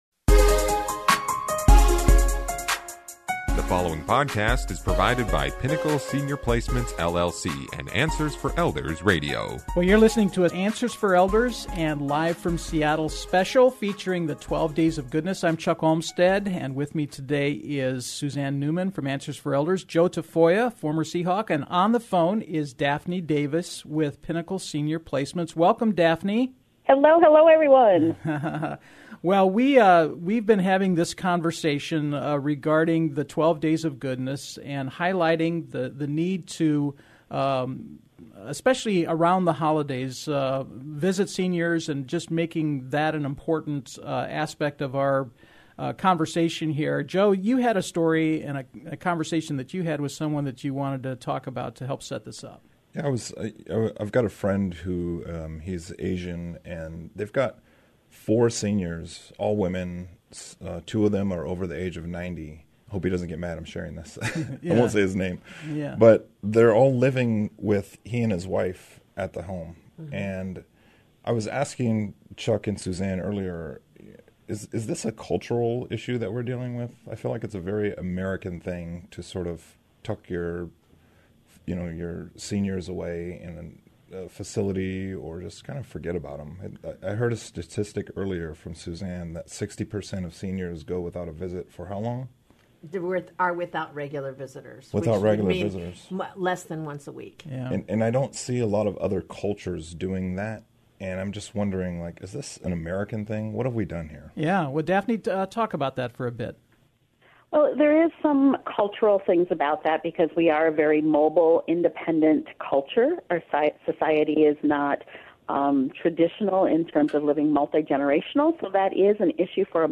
We are having a conversation about the need around the holidays to visit seniors.